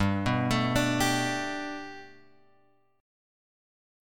Gadd9 chord {3 2 x 2 3 3} chord